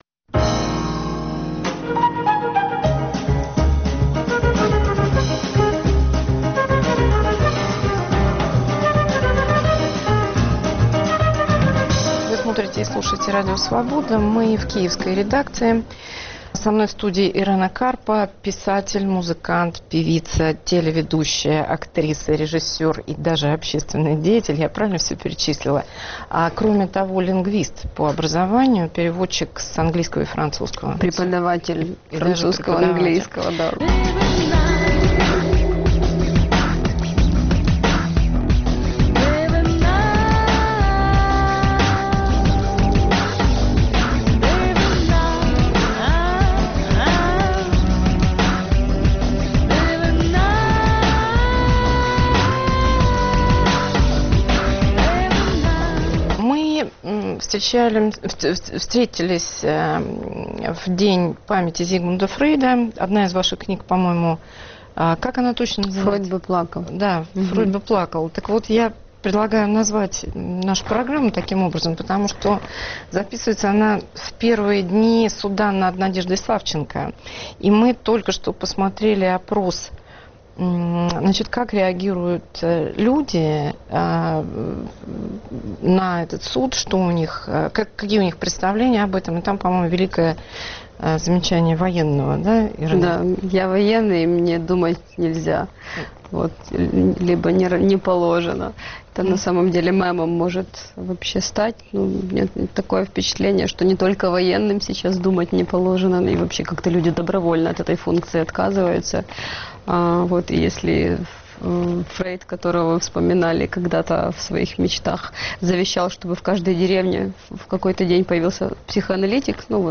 Ведет программу Елена Фанайлова